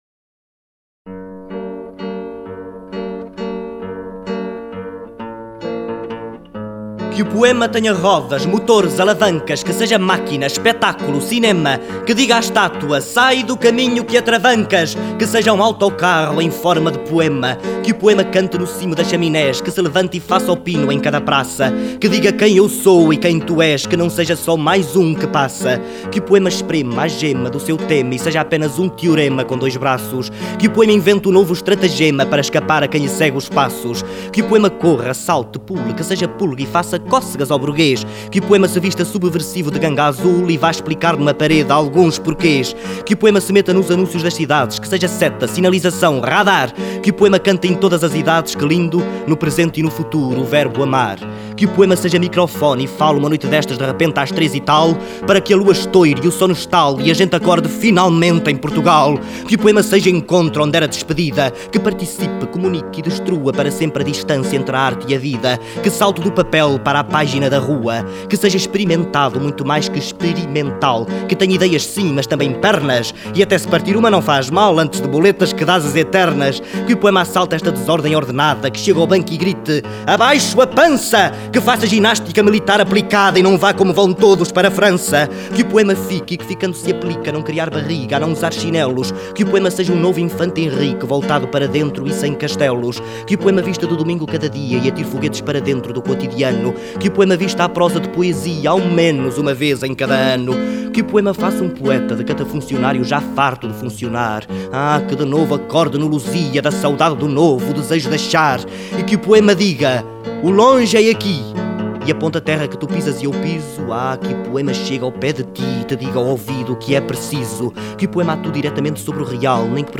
Neste poema, Manuel Alegre profetiza o 25 de abril muitos anos antes dele suceder: �Que o poema seja microfone e fale/ uma noite destas de repente �s tr�s e tal/ para que a lua estoire e o sono estale/ e a gente acorde finalmente em Portugal.� Aqui fica aqui a vers�o de "Poemarma" dita por M�rio Viegas. Documentos "Poemarma" dito por M�rio Viegas 6607 Kb Partilhar: